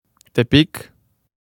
Tepic (Spanish pronunciation: [teˈpik]
TepicPronunciation.ogg.mp3